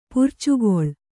♪ purcugoḷ